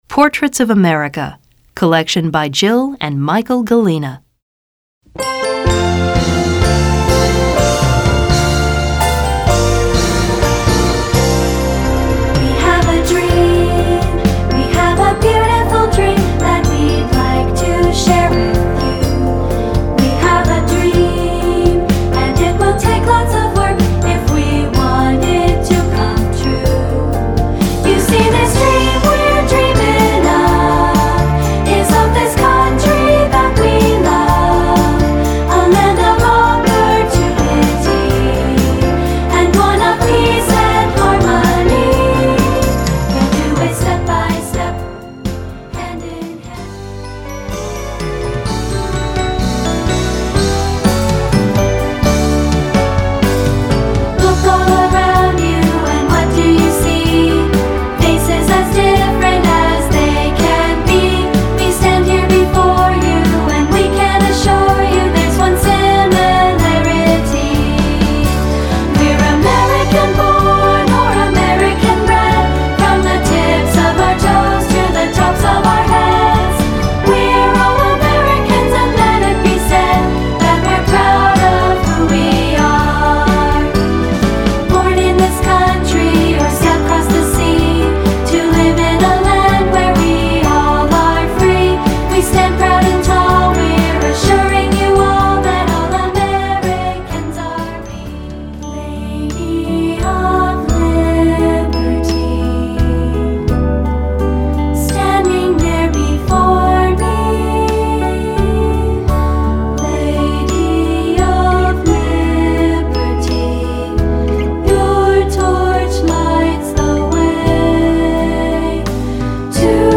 General Music Song Collections Patriotic Celebrate America
2-part choral arrangements with piano accompaniment